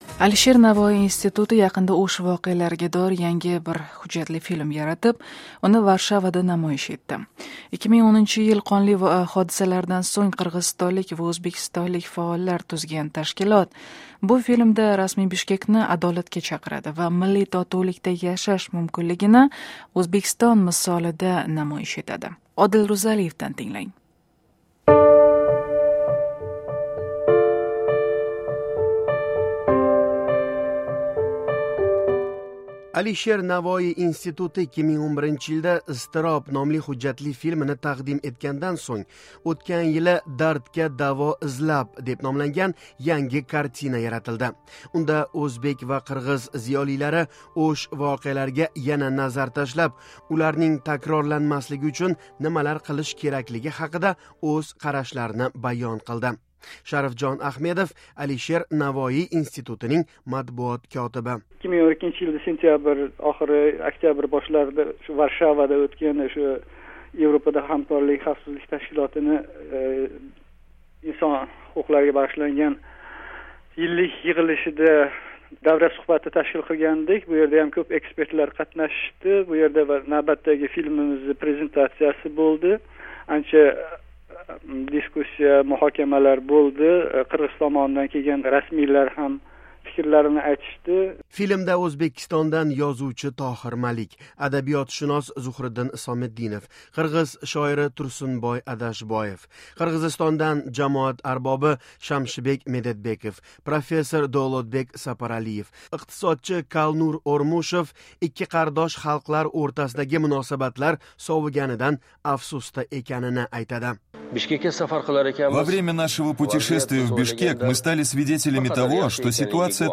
"Dardga davo izlab" filmi haqida suhbat